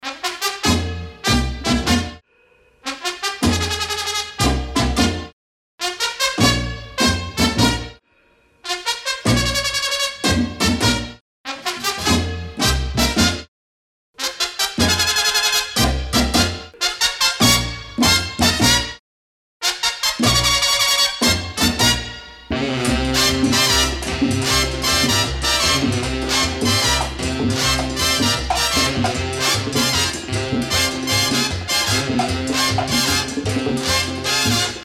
• Качество: 320, Stereo
без слов